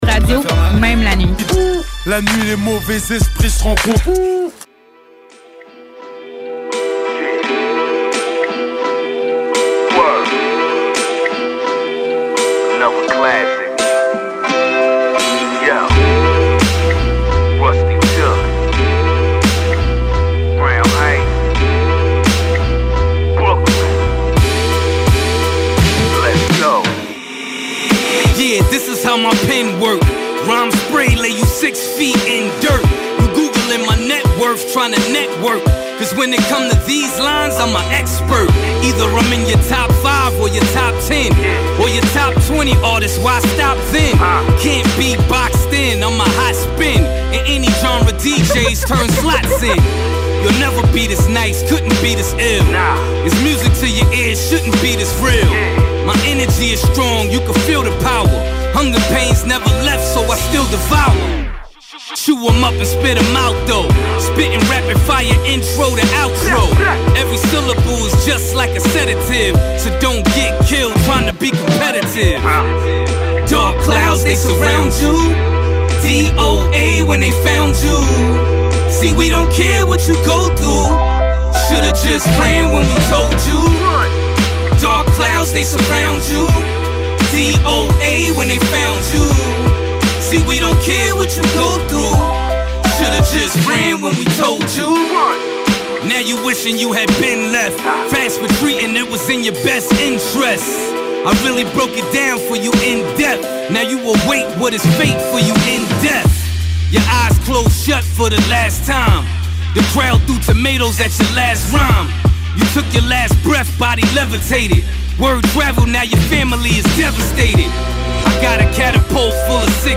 Restez réveillé ou réveillez-vous grâce à une sélection musicale alternative et unique.